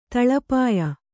♪ taḷapāya